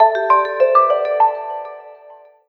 telephone.mp3